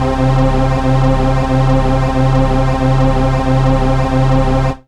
SYNTH GENERAL-2 0005.wav